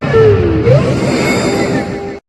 Cri de Bamboiselle dans Pokémon HOME.